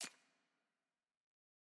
sfx_ui_research_panel_click.ogg